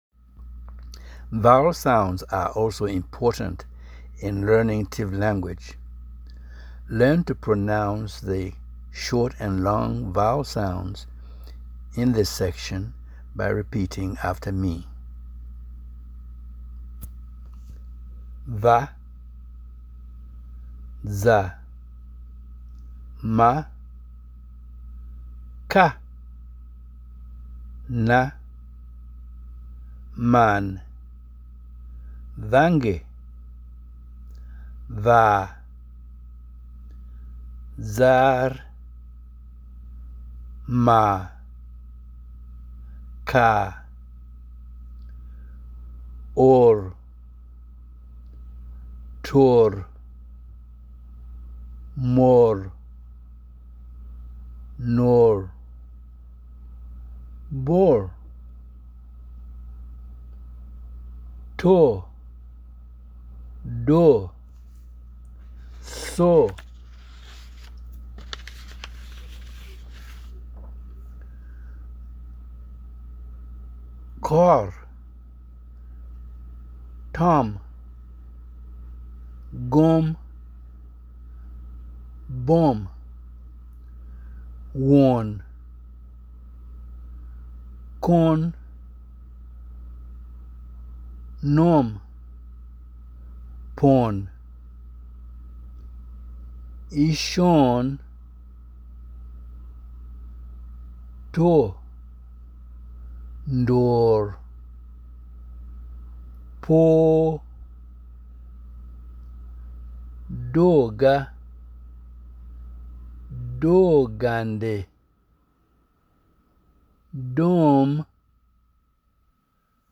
vowel sounds.mp3